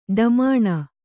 ♪ ḍamāṇa